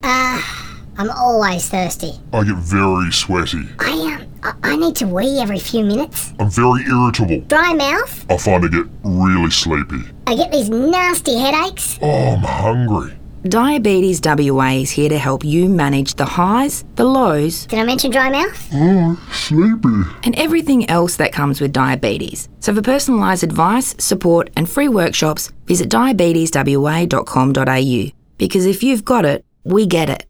A radio ad explores the ‘highs’ and ‘lows’ of Diabetes.
Diabetes-WA-radio-ad.mp3